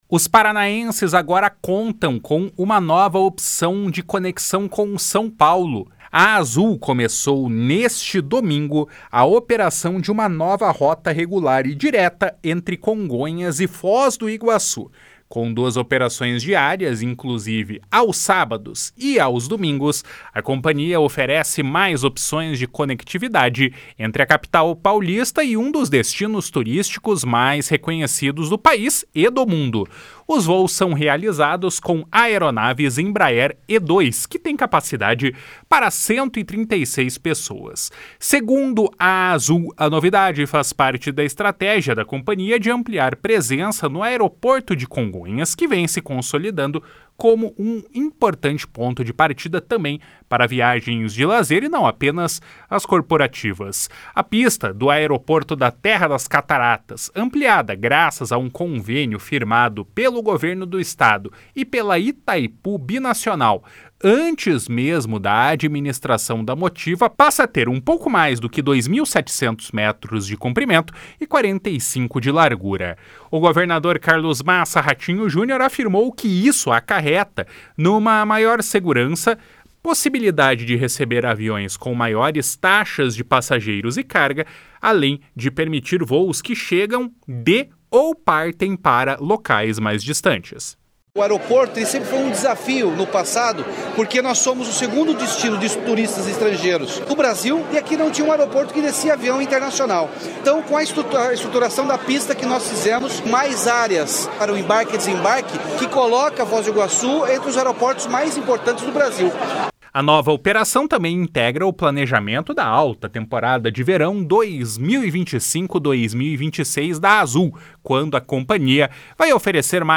// SONORA RATINHO JUNIOR //